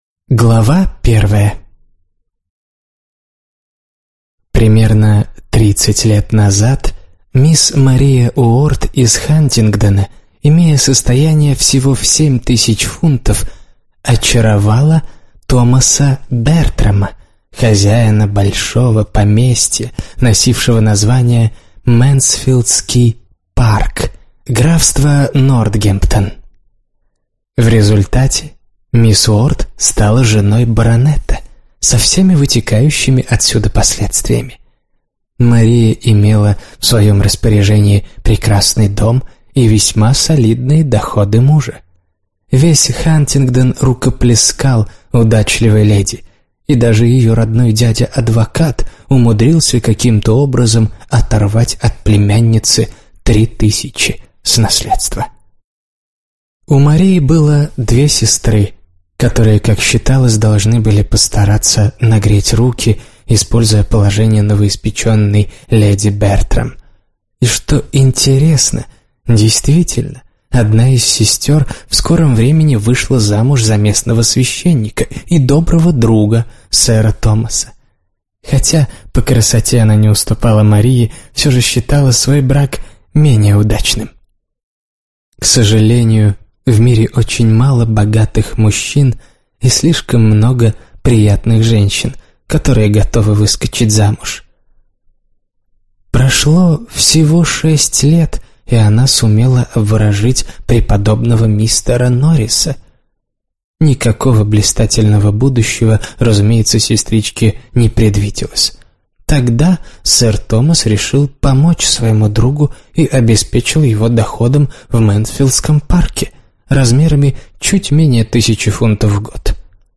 Аудиокнига Мэнсфилд-парк - купить, скачать и слушать онлайн | КнигоПоиск